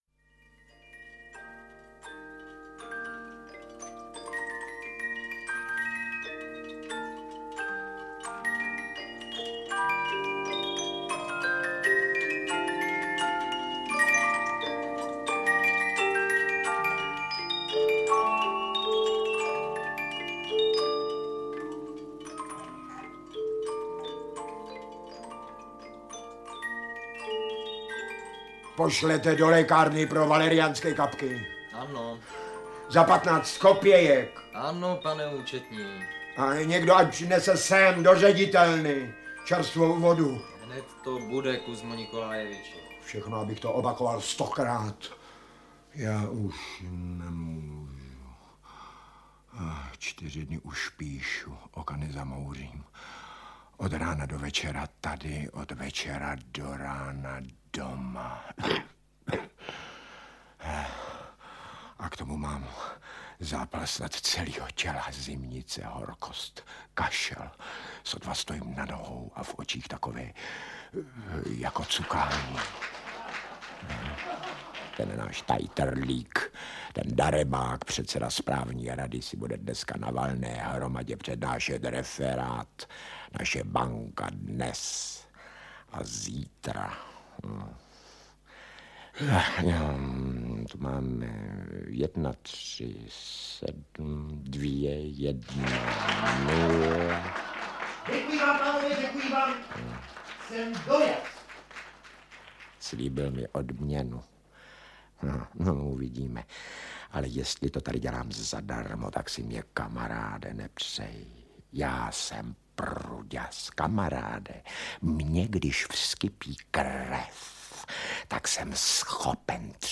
Výběr scén - Anton Pavlovič Čechov - Audiokniha